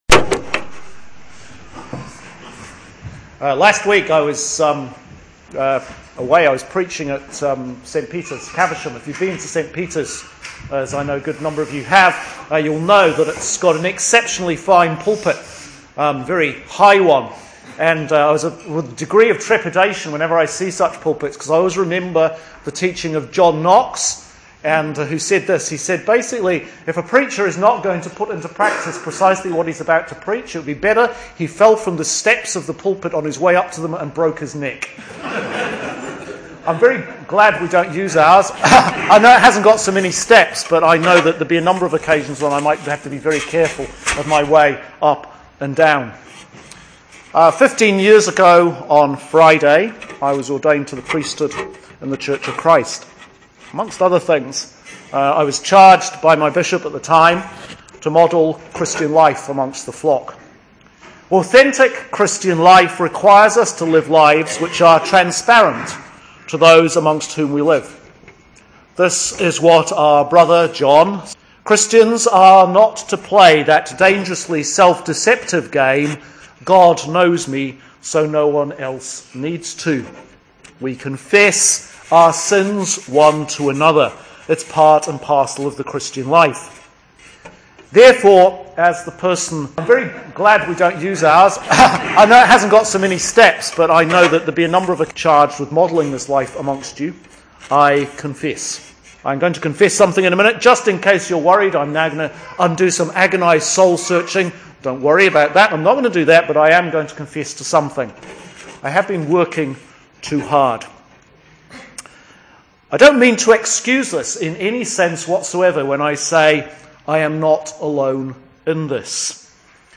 Sermon for Sunday July 6th 2014